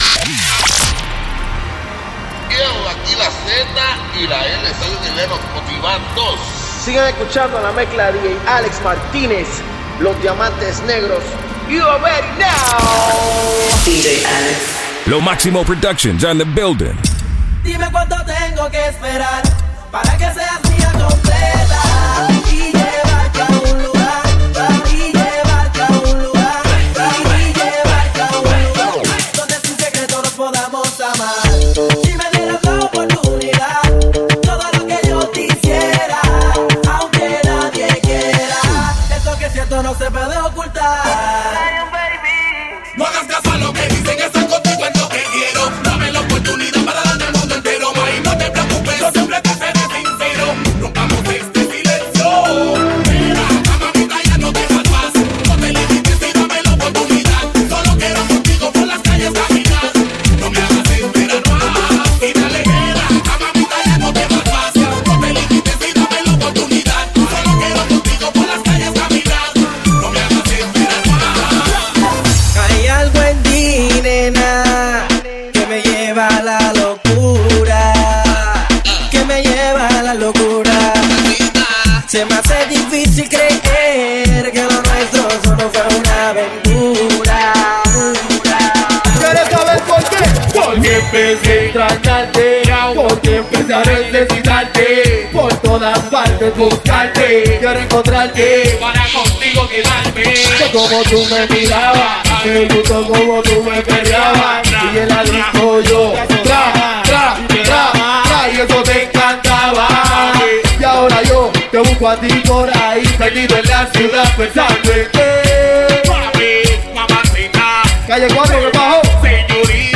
DJ Mixes